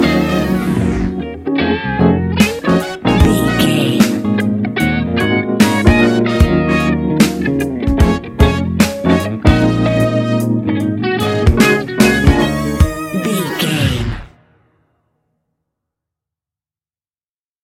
Fast paced
Uplifting
Ionian/Major
D♯